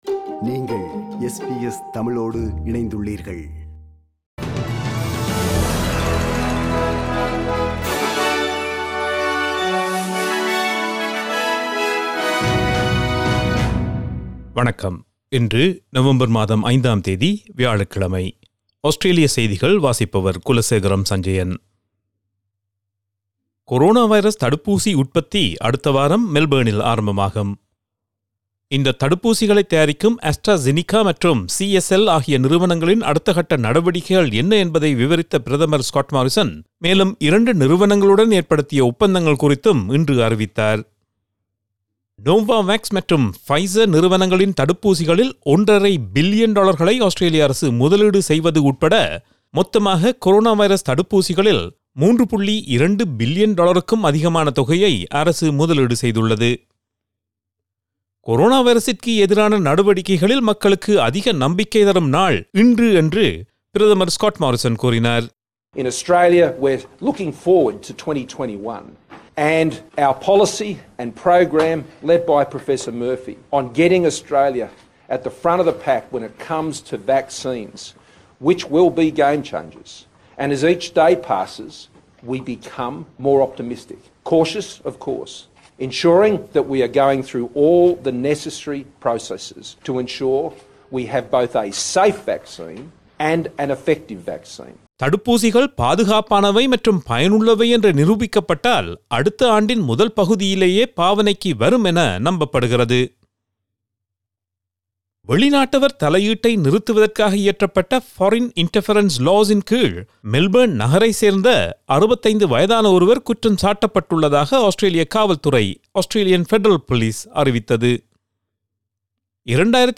Australian news bulletin for Thursday 05 November 2020.